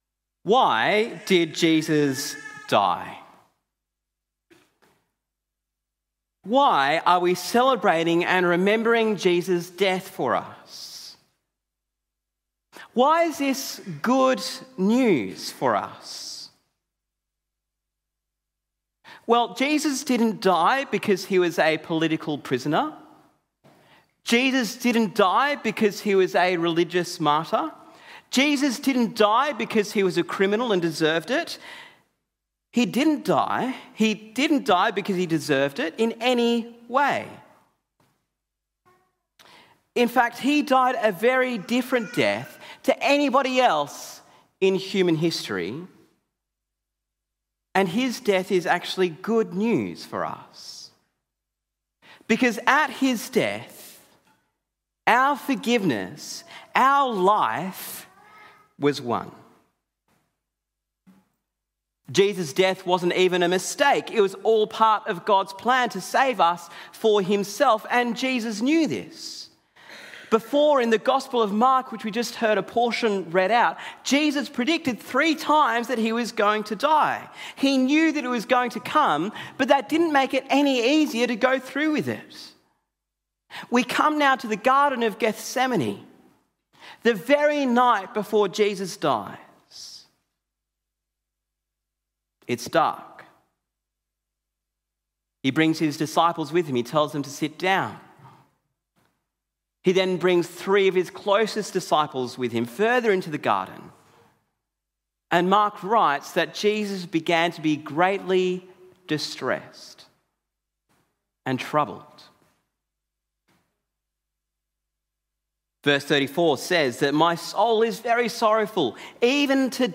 Sermon on Mark 14-15 - Good Friday
Good Friday These are three reflections from our Good Friday service in 2026 Download Sermon Transcript and Questions Download Series resource Download Sermon Audio See Other Sermons in Series